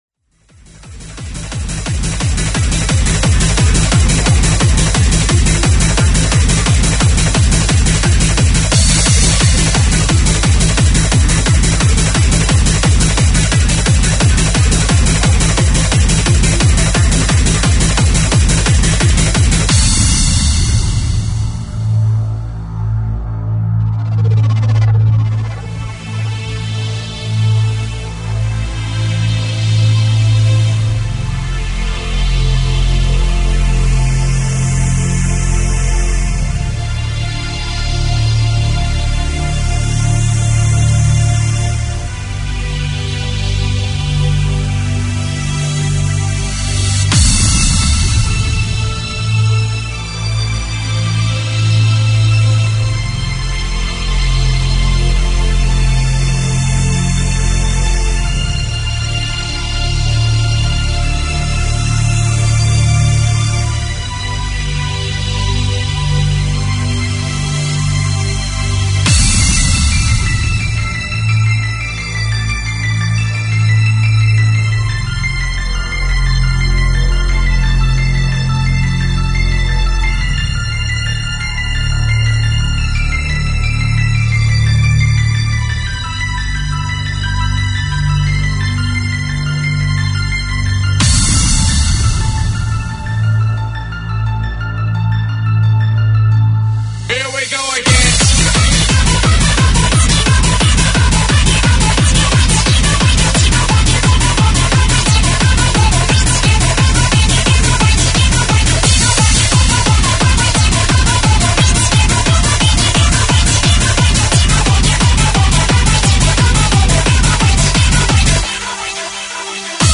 Freeform/Hardcore
Recorded at Kreatrix, Ottawa, Canada.